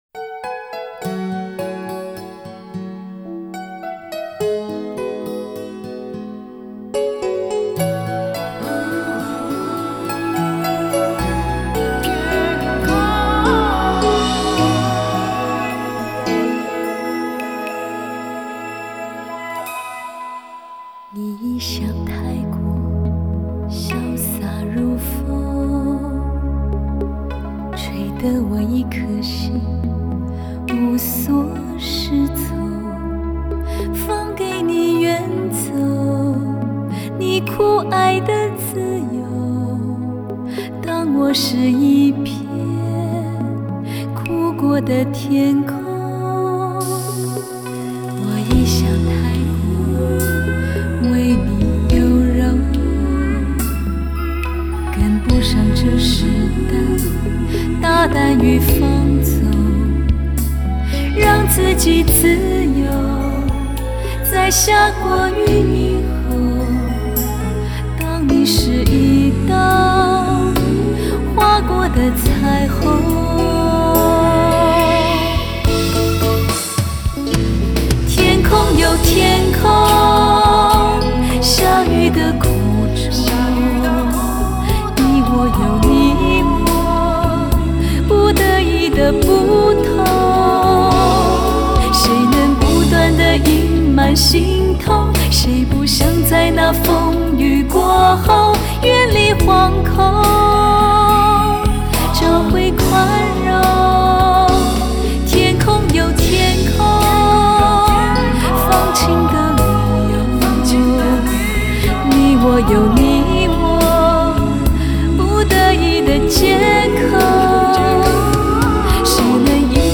Ps：在线试听为压缩音质节选，体验无损音质请下载完整版 作词